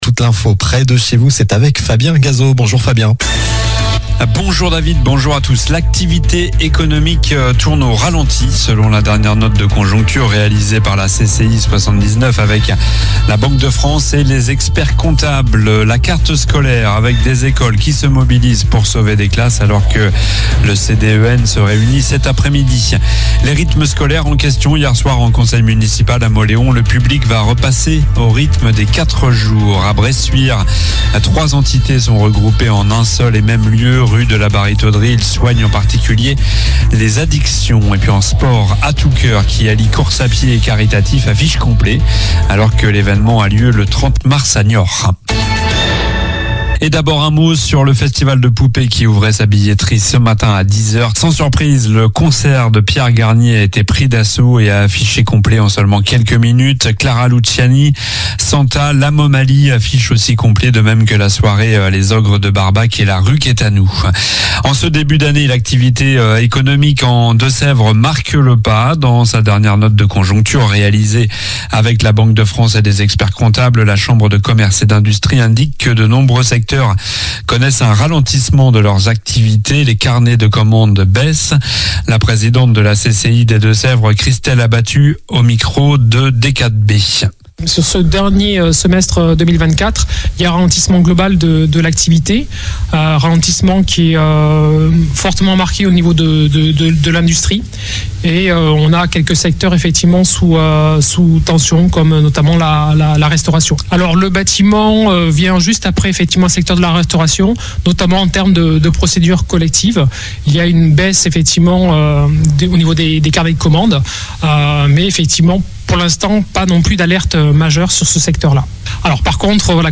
Journal du jeudi 20 février (midi)